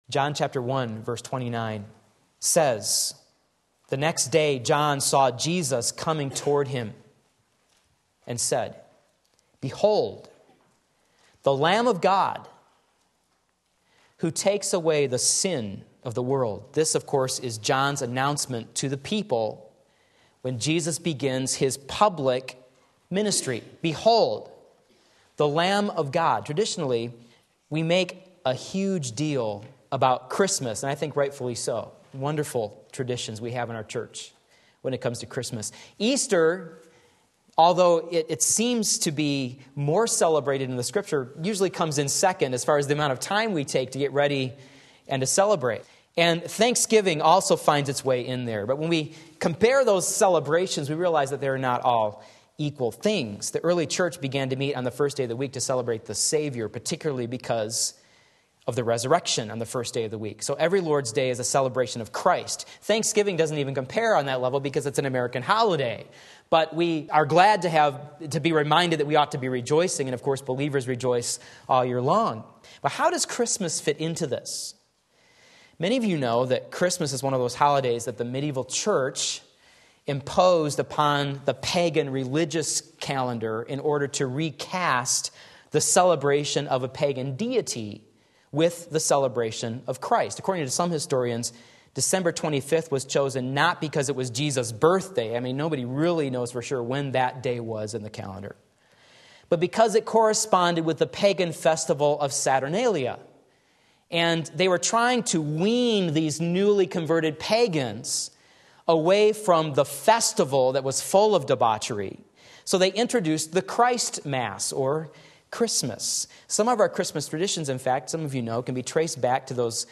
Sermon Link
Part 1 John 1:29 Sunday Morning Service